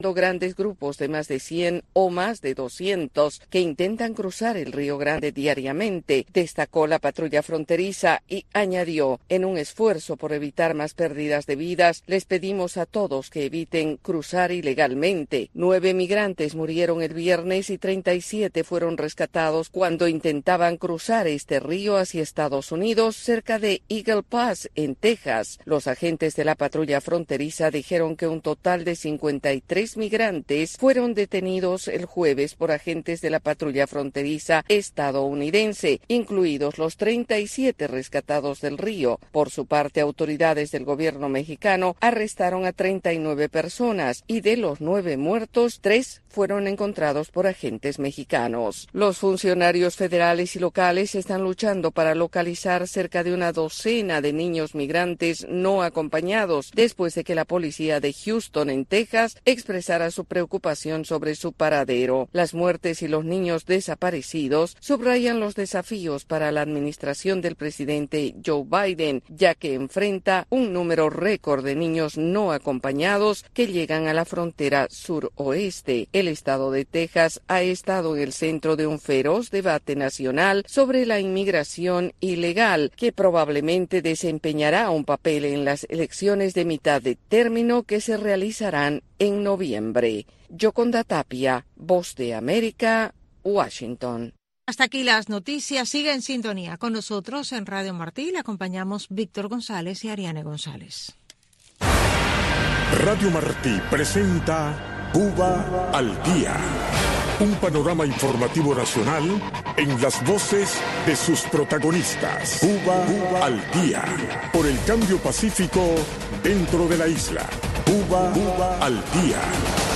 en vivo